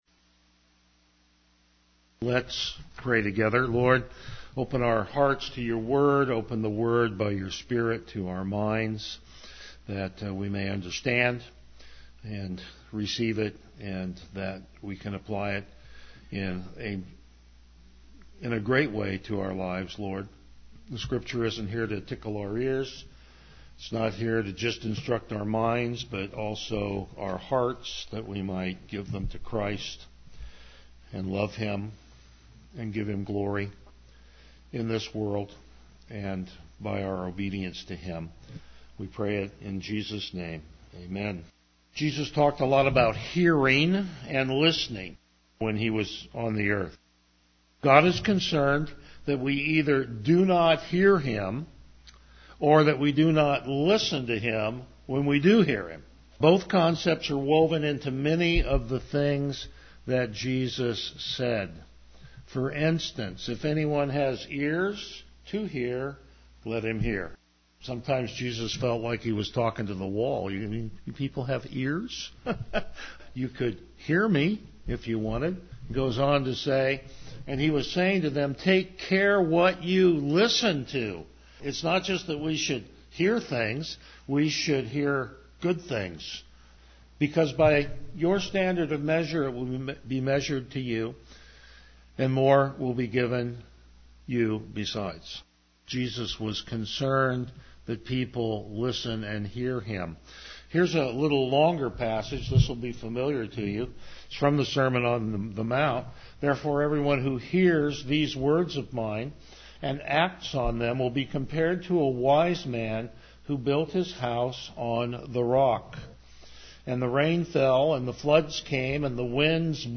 Passage: Hebrews 6:1-3 Service Type: Morning Worship